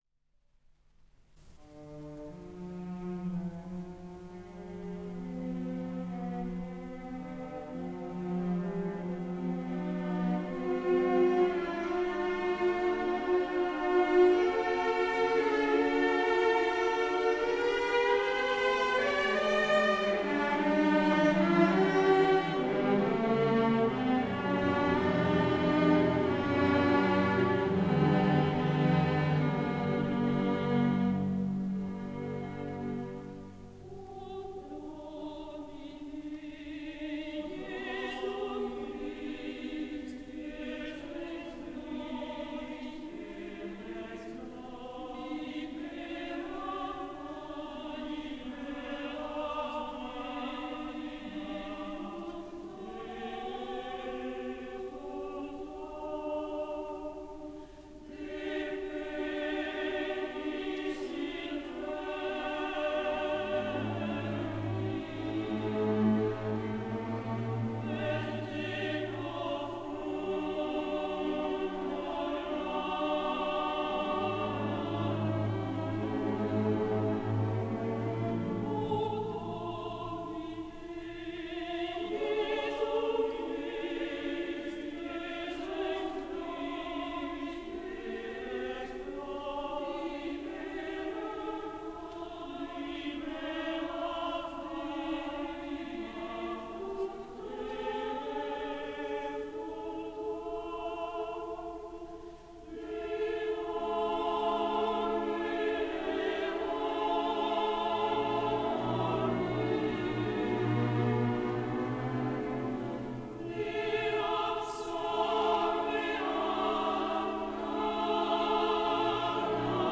Offertoirela(봉헌송)는 로 단조로 시작하며, 알토와 테너가 짧은 간격을 두고 이어지는 카논 풍의 선율로 시작한다.
반복되는 한 음으로 시작하지만, "fac eas, Domine, de morte transire ad vitamla"(주님, 그들을 죽음에서 생명으로 건너가게 하소서)라는 구절에서는 더욱 선율적으로 청원한다. 합창단은 처음에 제시된 모티프로 첫 구절을 반복하지만, 4성부의 다성음악으로 더욱 정교하게 연주되며, 로 장조의 웅장한 아멘(Amen)으로 마무리된다.[10]